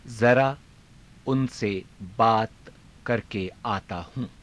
ゆっくり ふつう